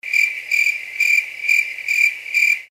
звуки животных
звуки природы
Звук кузнечика или сверчка.